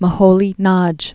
(mə-hōlē-nŏj, mōhoi-nŏdyə), László 1895-1946.